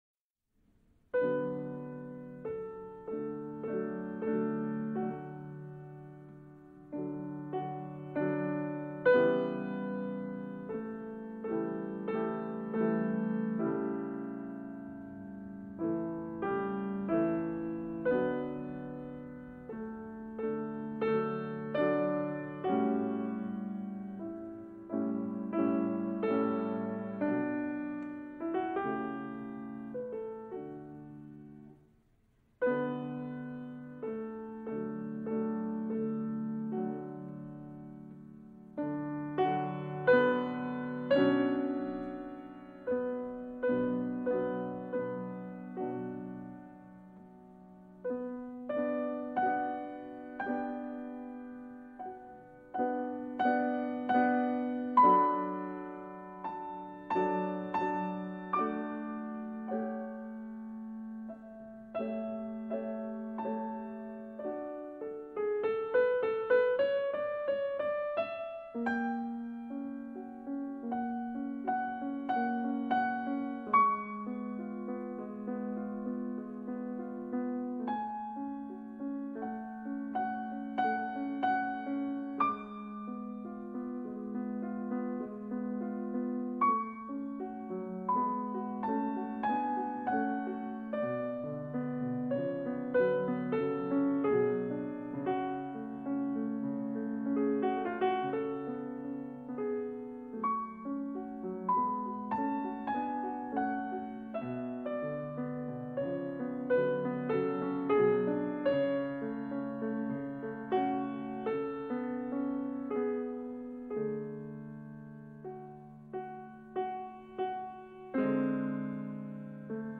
Campagne Naturelle : Pluie Maison